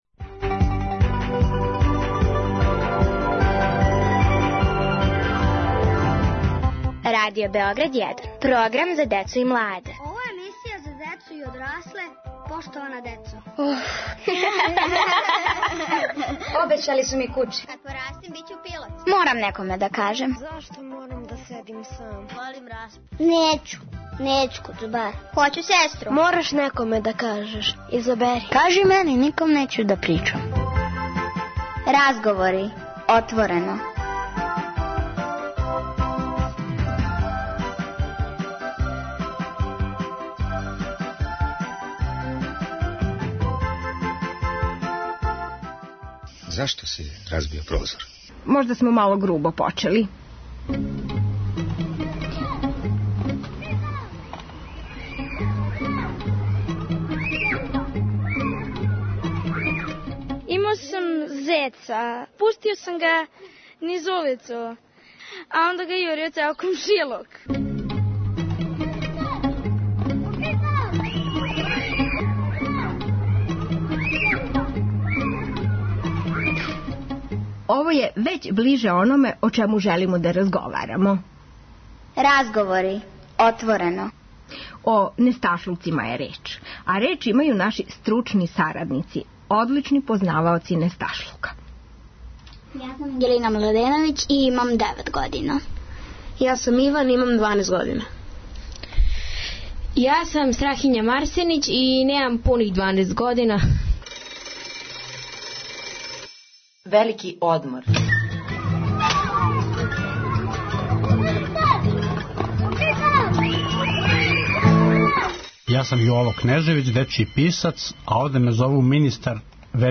Ако у вашој кући није скоро било добрих несташлука, придружите нам се. Екипу која је обећала да ће нам се придружити у недељним отвореним разговорима чине прави стручњаци за ову осетљиву тему.